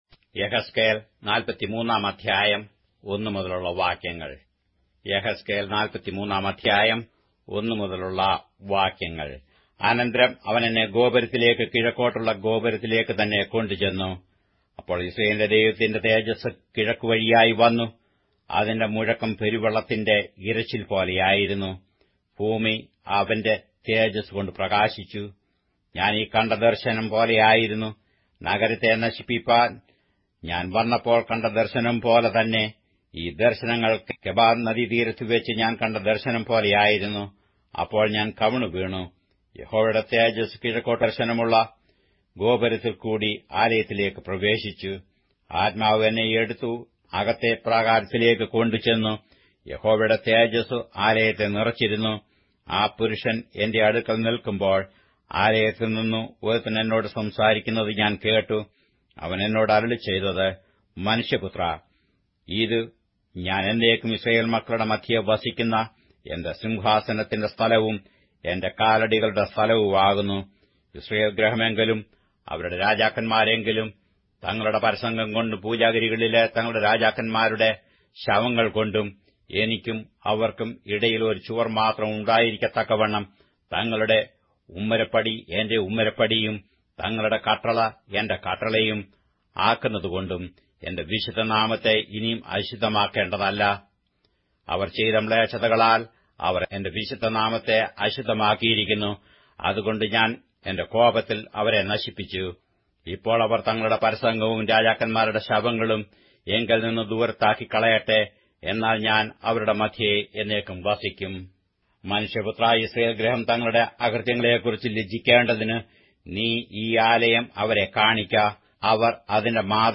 Malayalam Audio Bible - Ezekiel 36 in Gnttrp bible version